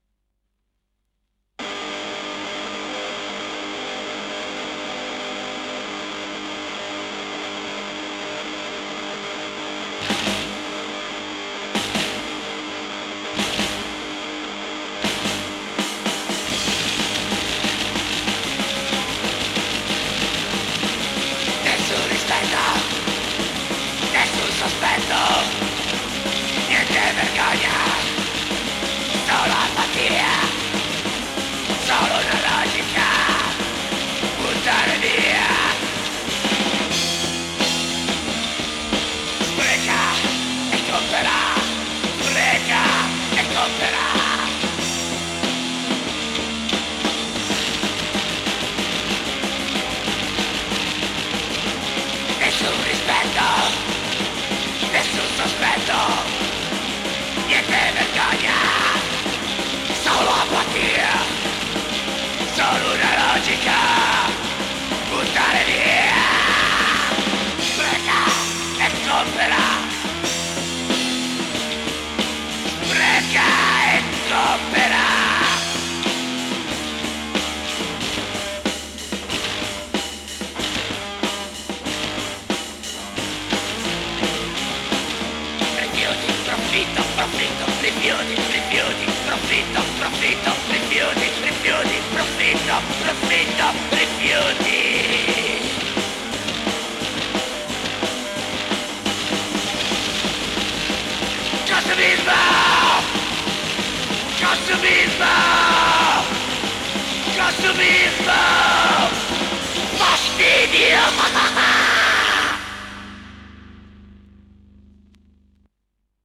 recensioni e punk hardcore italiano dal 2003.